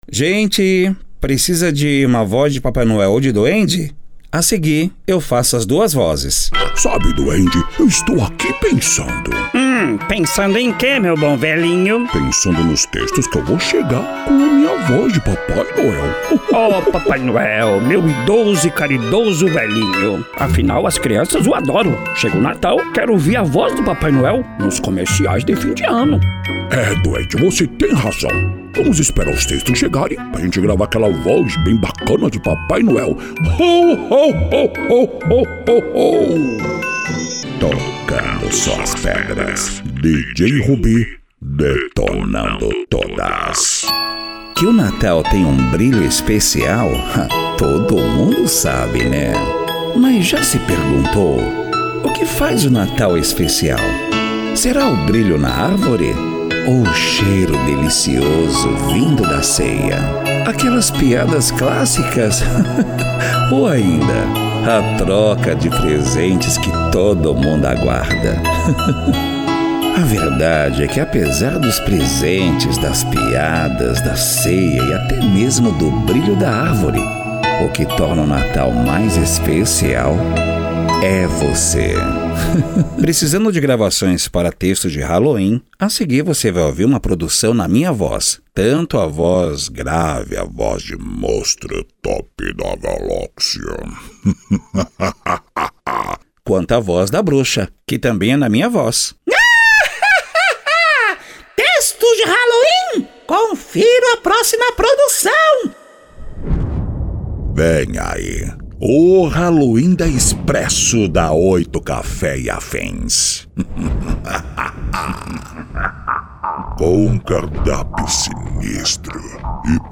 Estilo(s): Padrão Animada Varejo